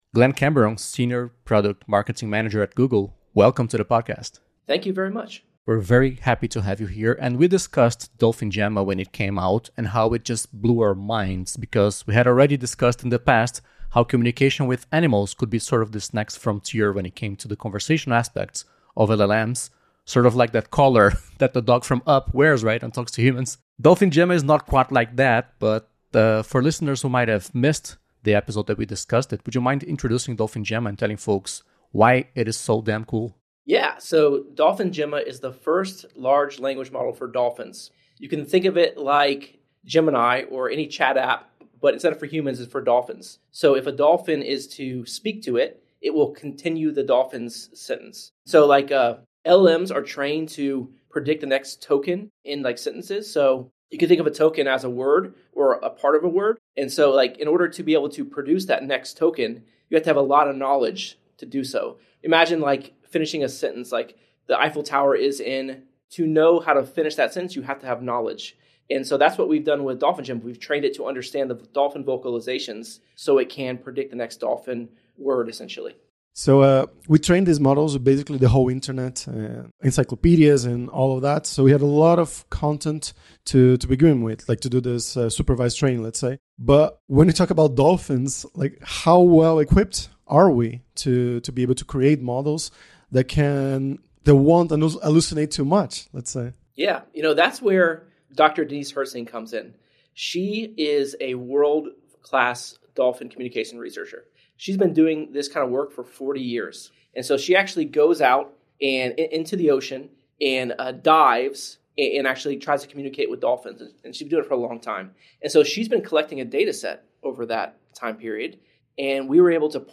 Nesta semana, mergulhamos em duas entrevistas absolutamente fascinantes sobre o projeto do Google com o Wild Dolphin Project que desenvolveu o primeiro LLM para golfinhos.